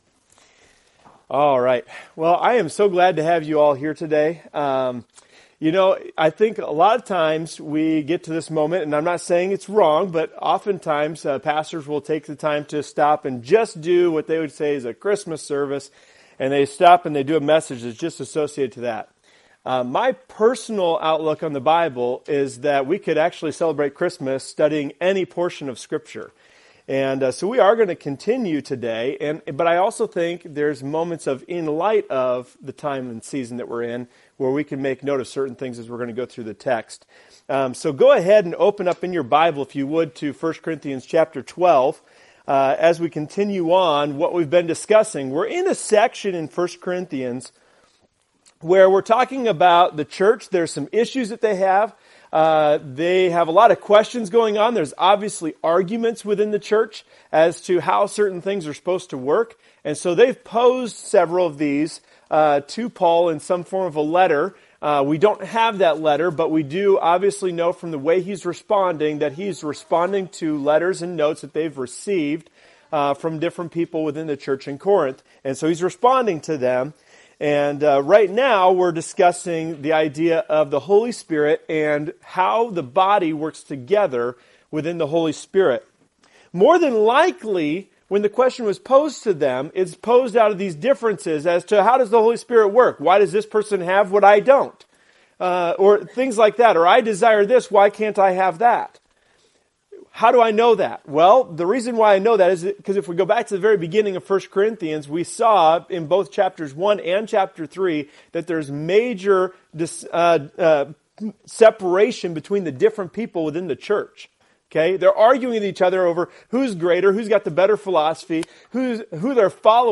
Services